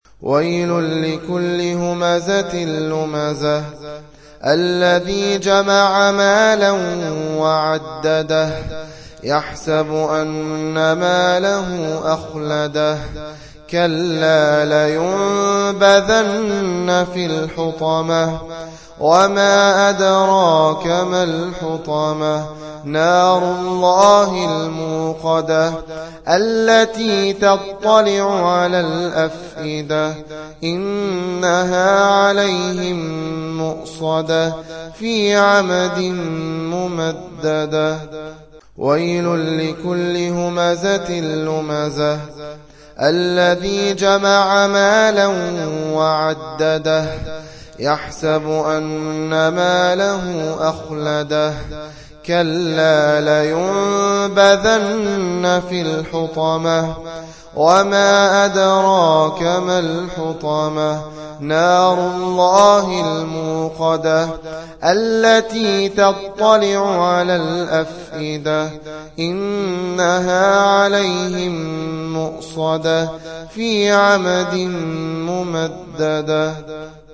تلاوات
رواية : حفص عن عاصم